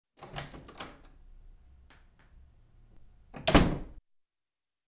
Звуки хлопанья дверью
Открывание и закрывание двери из дерева